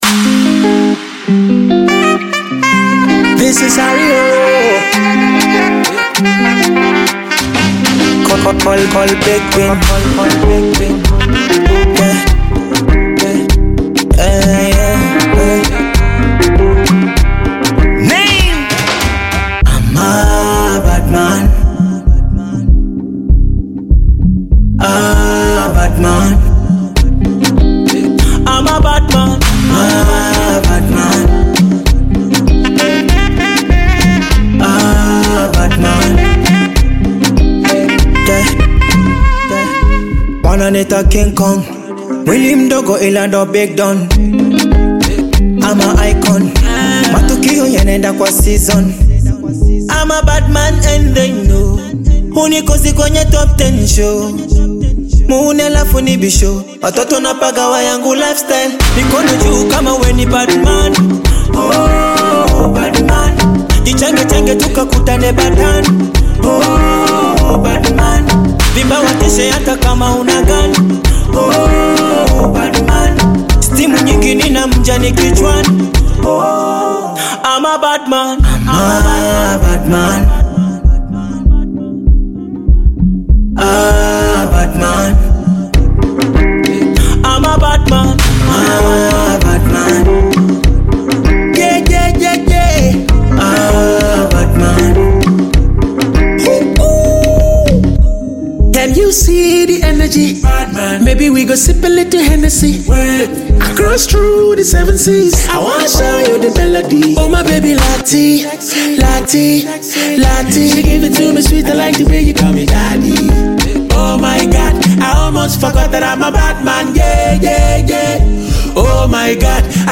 Bongo Flava artist
African Music